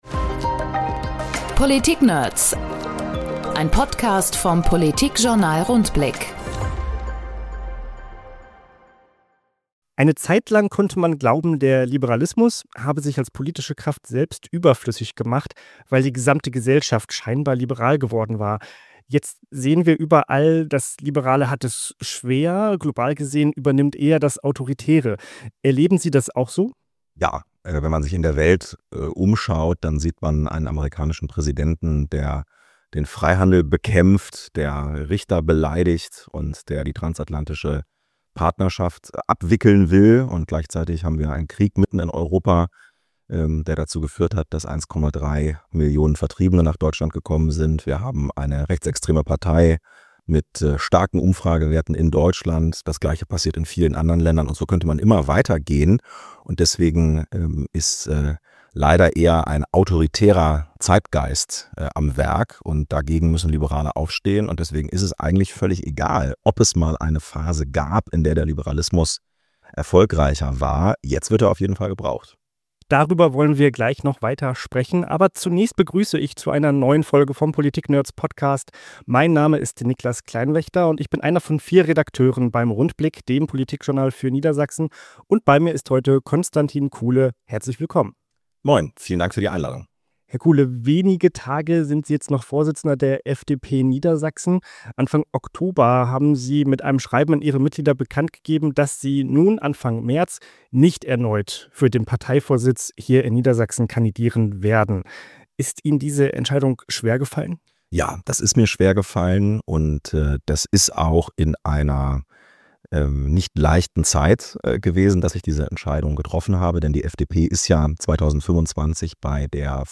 Ein Gespräch über den Liberalismus, die Liberalen und das Leben nach dem Bundestag